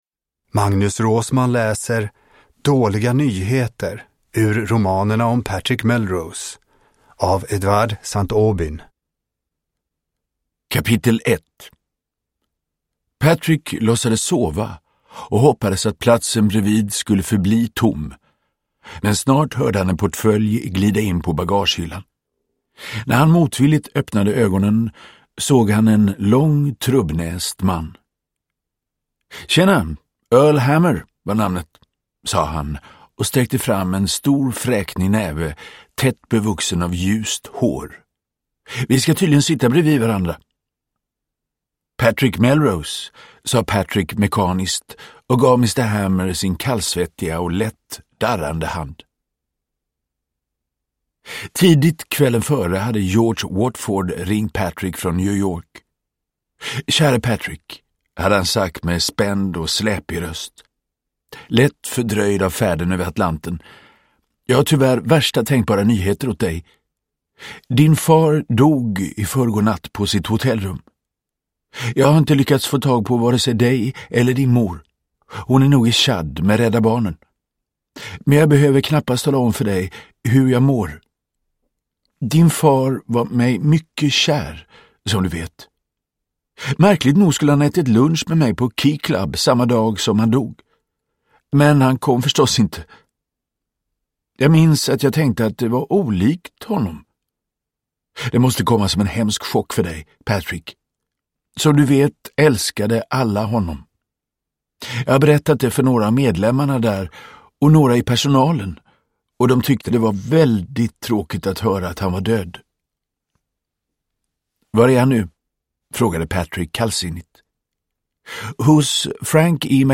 Dåliga nyheter – Ljudbok – Laddas ner